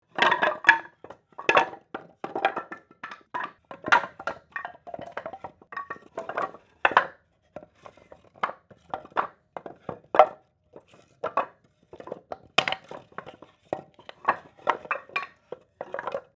Download Foley sound effect for free.
Foley